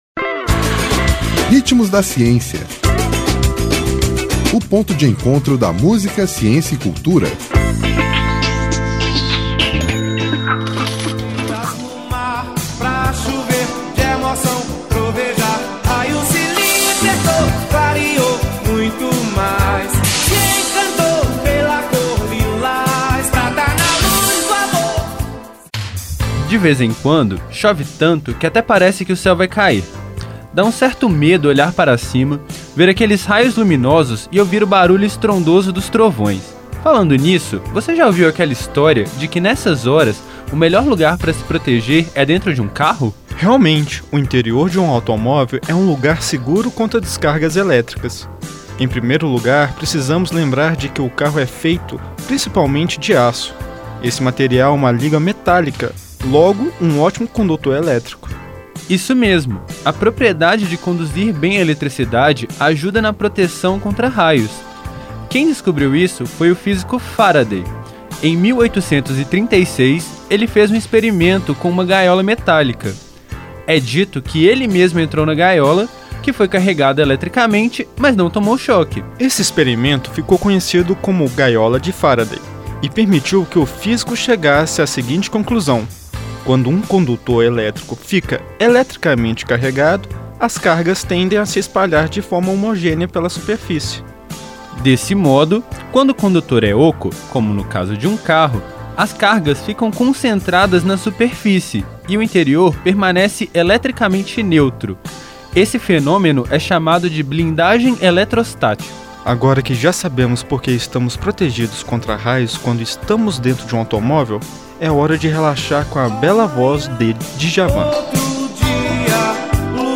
Nome da música: Lilás